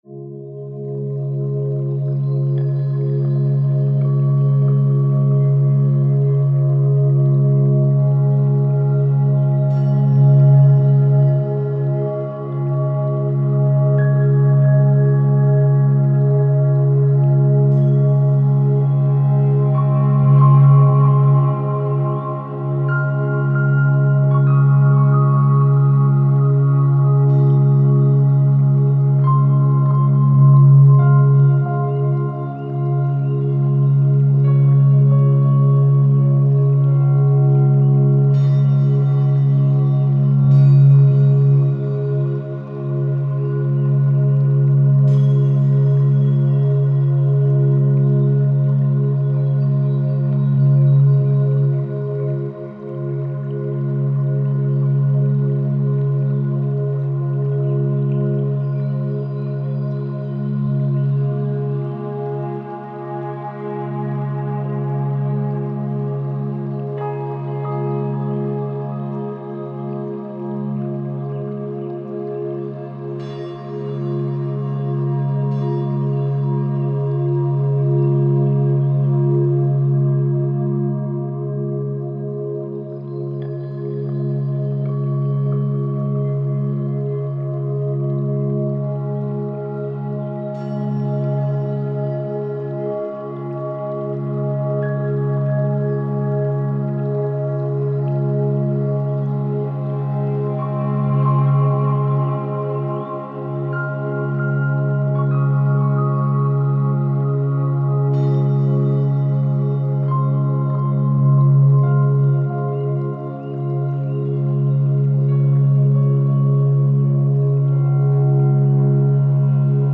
528 Hz